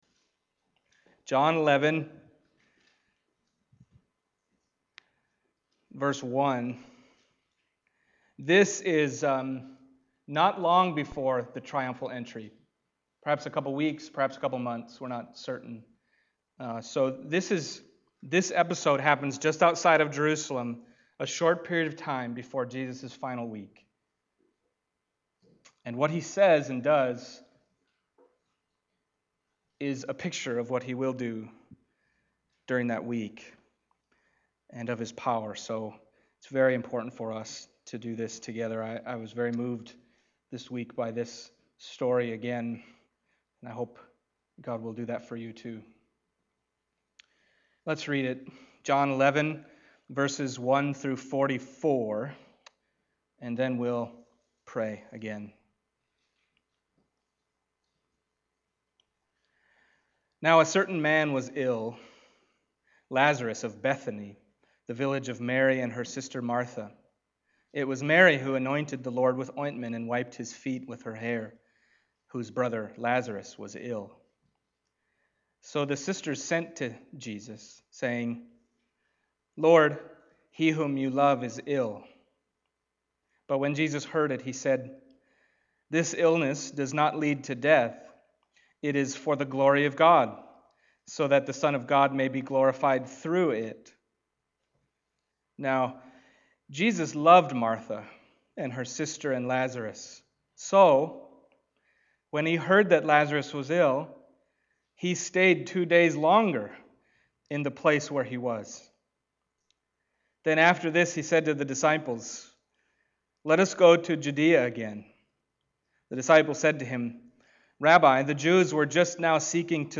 John Passage: John 11:1-44 Service Type: Sunday Morning John 11:1-44 « You Have the Words of Eternal Life Do Not Disbelieve